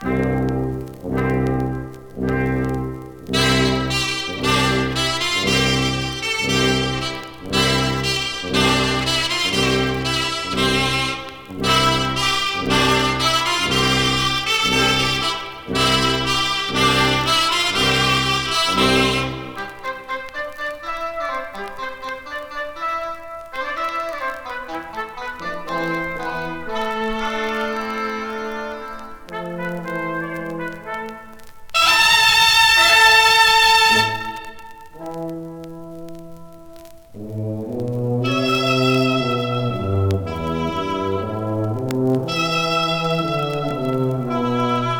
旅情とダイナミックさが楽しいスペイン編。
Popular, Classical, Jazz　USA　12inchレコード　33rpm　Mono